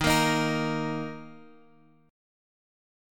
D#5 chord {x 6 x 3 4 6} chord
Dsharp-5th-Dsharp-x,6,x,3,4,6.m4a